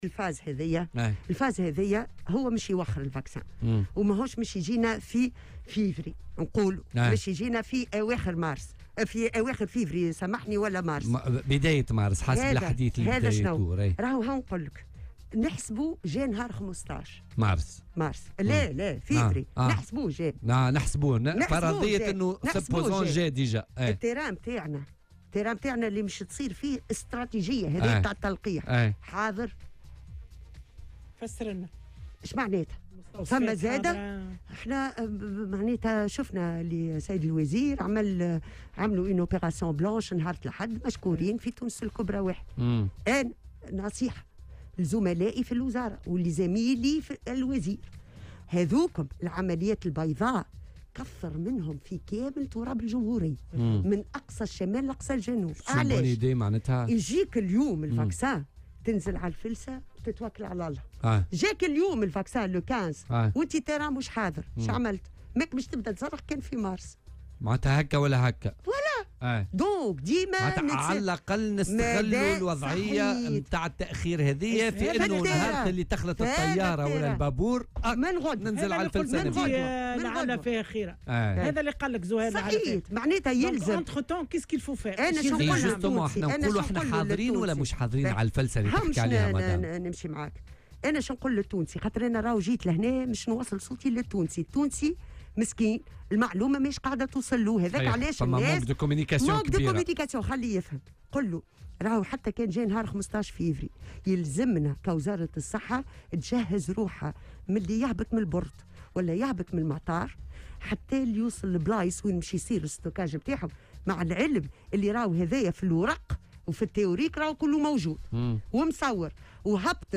وأوضحت في مداخلة لها اليوم في برنامج "بوليتيكا" أنه يجب في الأثناء إعداد الأرضية اللازمة والتكثيف من العمليات البيضاء في كامل تراب الجمهورية استعدادا لانطلاق حملات التطعيم.